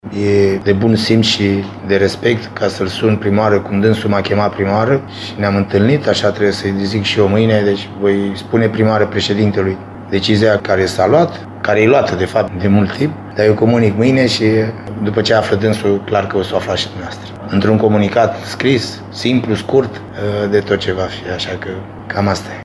La finalul jocului, antrenorul dobrogenilor, Gheorghe Hagi, a vorbit despre postul de selecționer pe care i l-a oferit președintele FRF, Răzvan Burleanu: